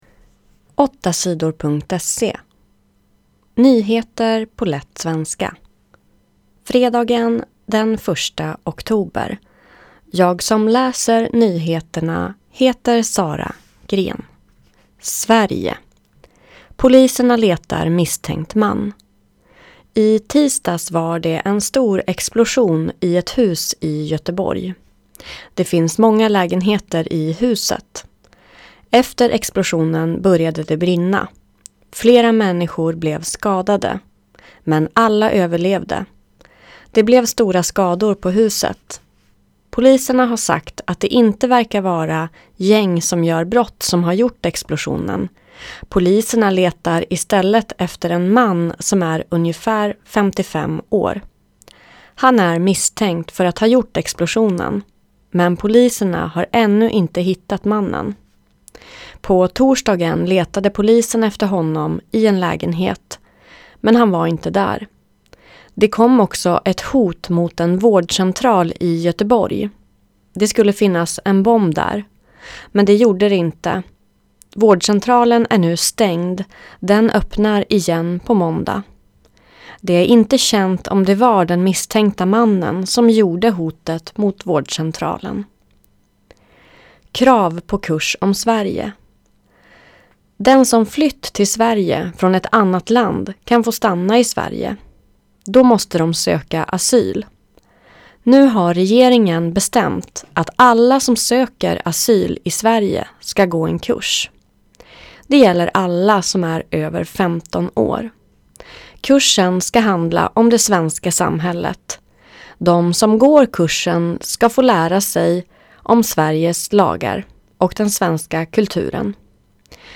Nyheter på lätt svenska den 1 oktober